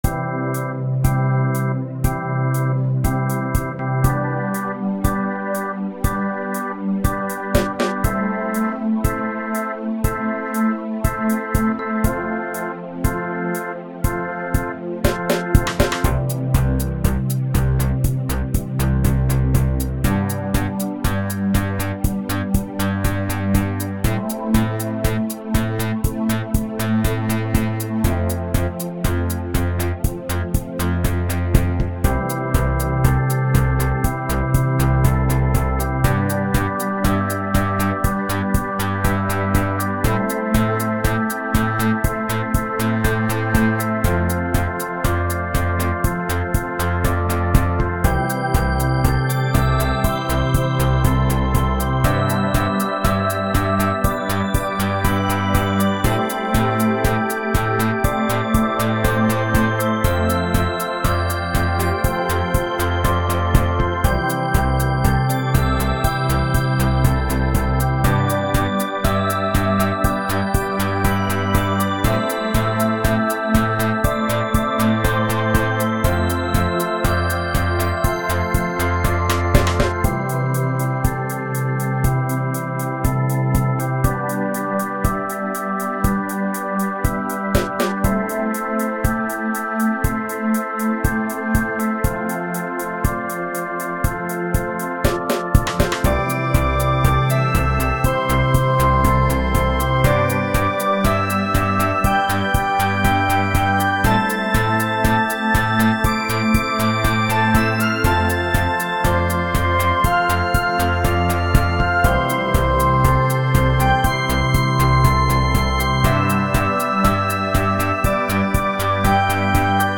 All the drums have been performed by Hydrogen.